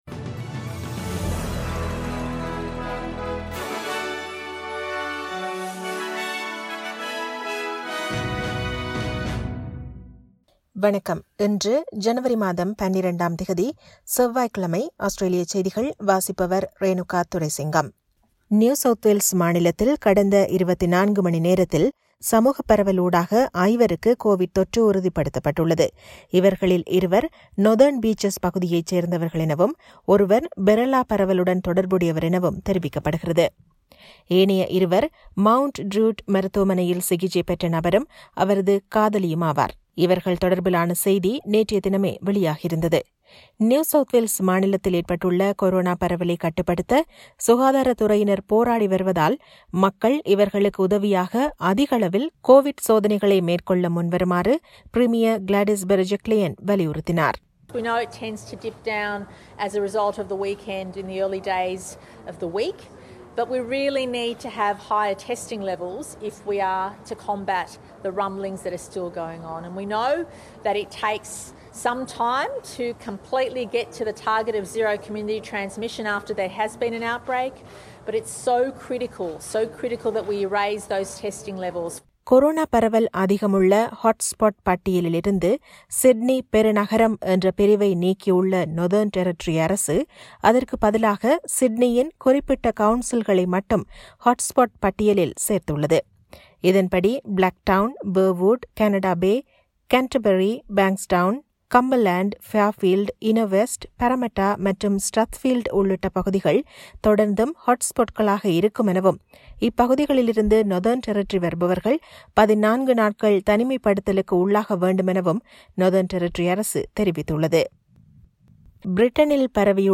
Australian news bulletin for Tuesday 12 January 2021.